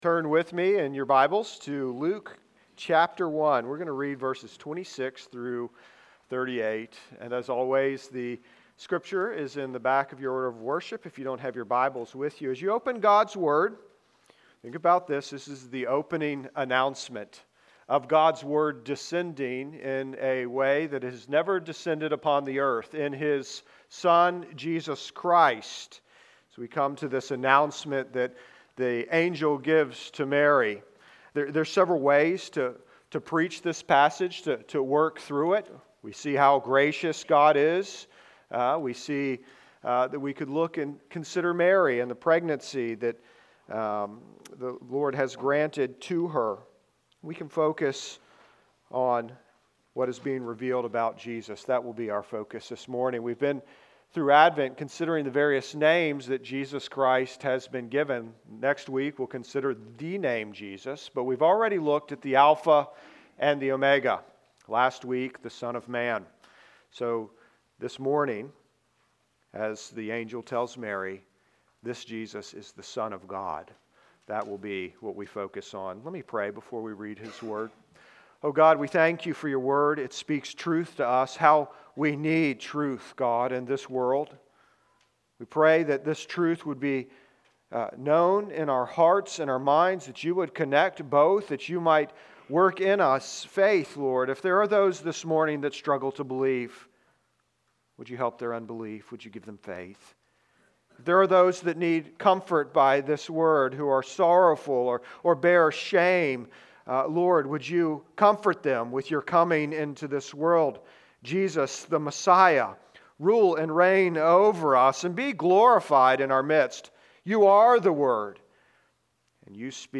Son Of God (Luke 1:26-38) Sermons And Lessons From All Saints Presbyterian Church podcast